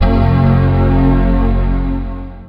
OCEFIAudio_VoiceOver_Boot.wav